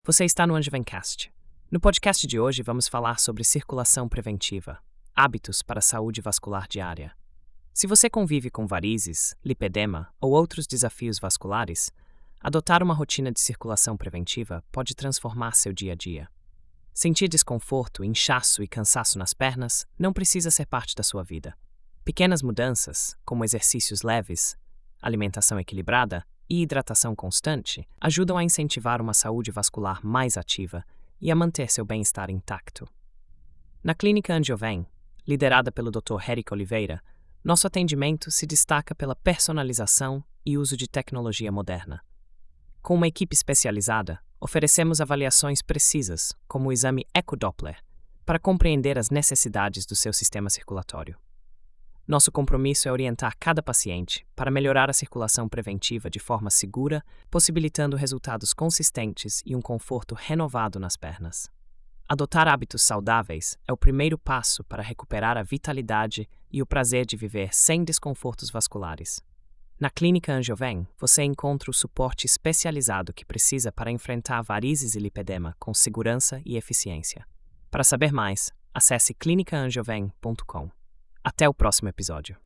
Narração automática por IA